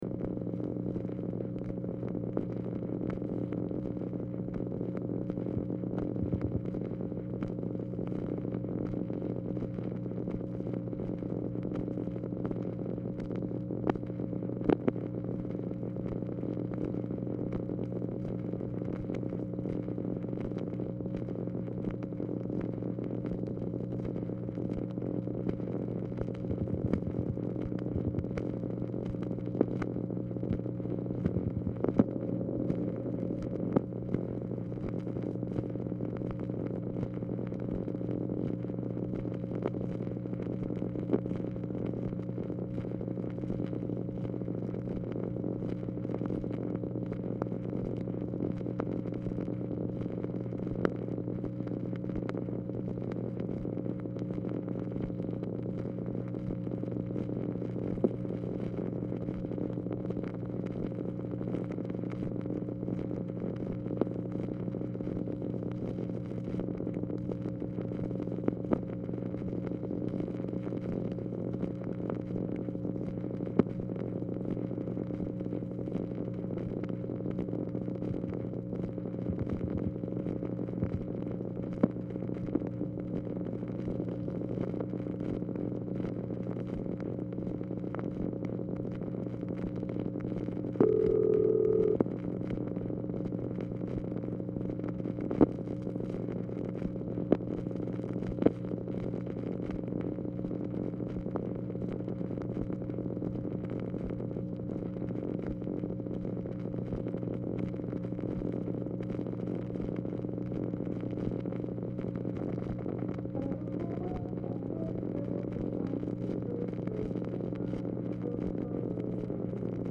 Telephone conversation # 7198, sound recording, OFFICE NOISE, 3/31/1965, time unknown | Discover LBJ
MUSIC AUDIBLE IN BACKGROUND
Format Dictation belt
Location Of Speaker 1 Mansion, White House, Washington, DC
Specific Item Type Telephone conversation